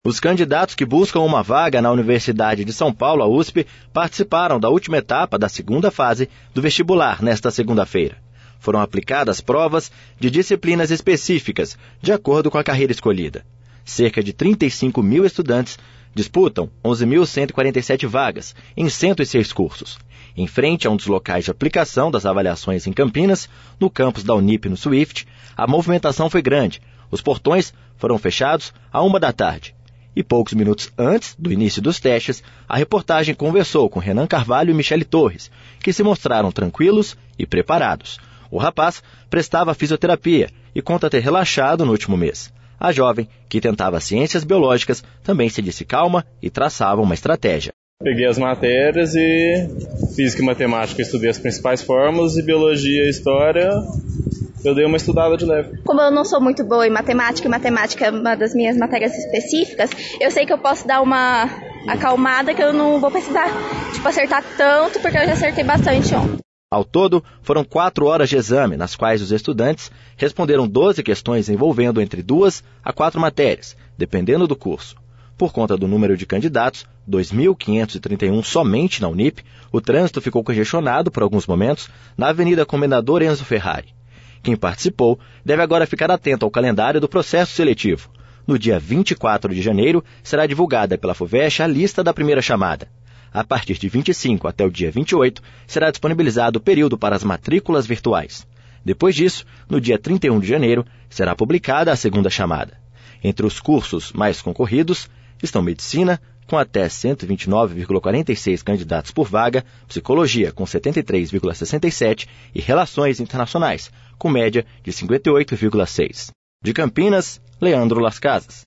Em frente a um dos locais de aplicação das avaliações em Campinas, no campus da Unip no Swift, a movimentação foi grande.